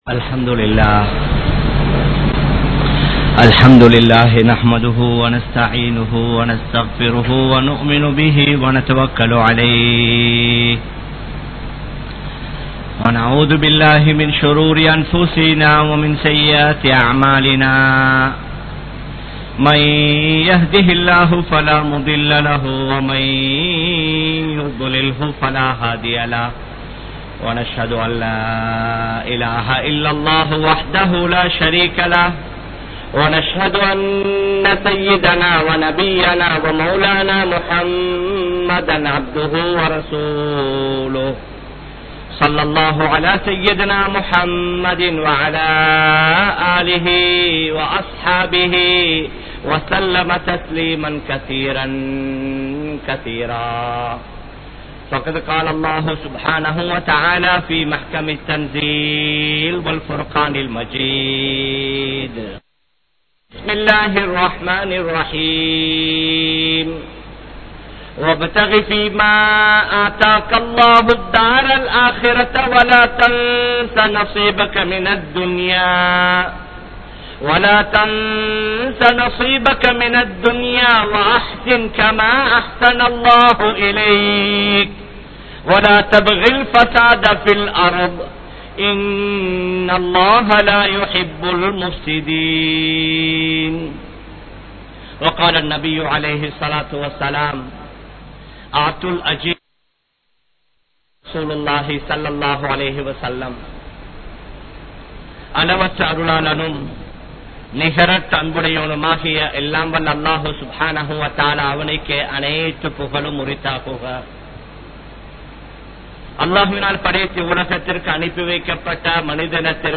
Islamiya Paarvaiel Muthalaali Tholilaali (இஸ்லாமிய பார்வையில் முதலாளி தொழிலாளி) | Audio Bayans | All Ceylon Muslim Youth Community | Addalaichenai